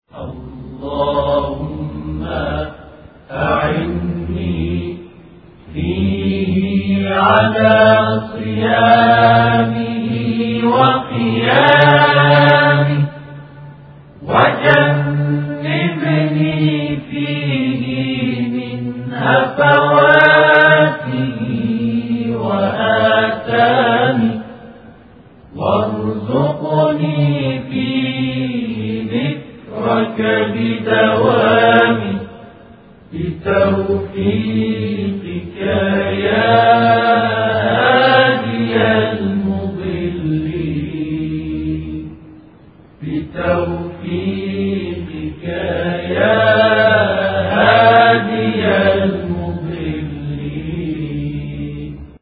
همخوانی دعای روز هفتم ماه مبارک رمضان + متن و ترجمه
در این محتوا، متن کامل دعای روز هفتم ماه مبارک رمضان به همراه ترجمه روان فارسی و صوت همخوانی ادعیه با صدایی آرامش‌بخش را دریافت کنید.